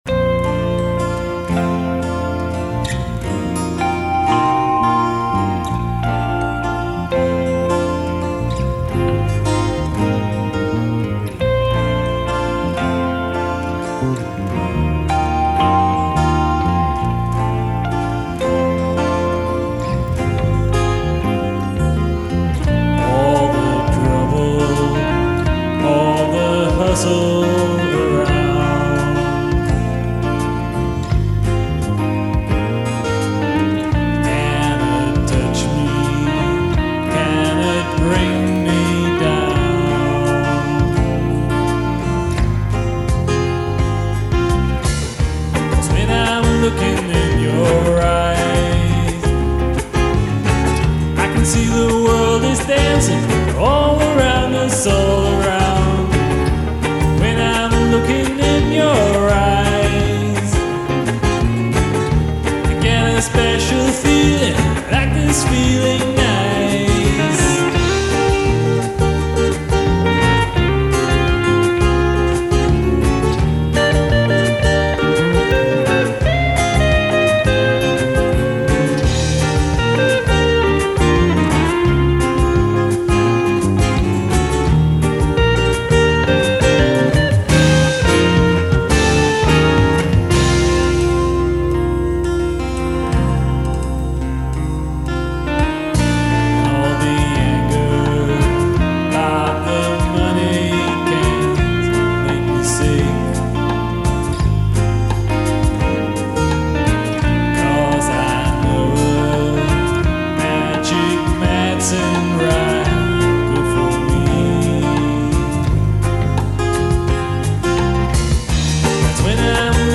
Ein außergewöhnlich heiteres Stück mit nur leicht dramatischem Mittelteil. Wohnzimmerproduktion auf Cassette von 1990.
Bass, Keys und Programming
Gitarre, Vocals